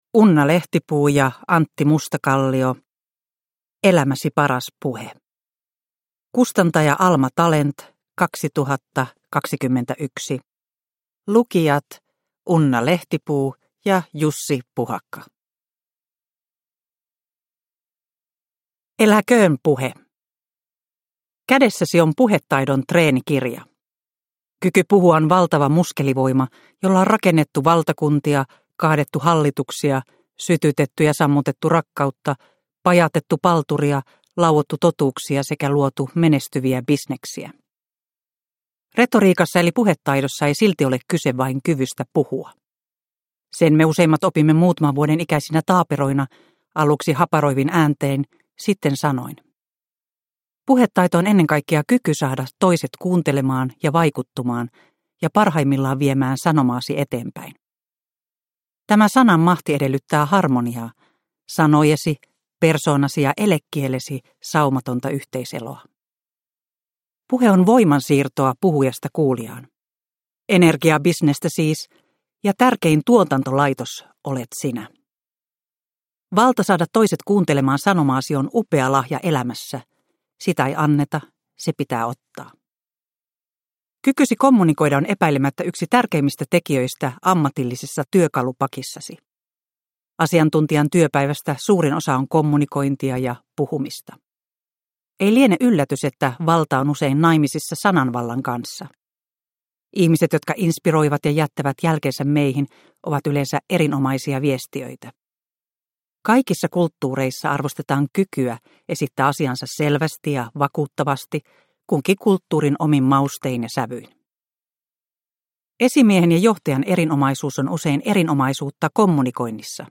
Elämäsi paras puhe – Ljudbok – Laddas ner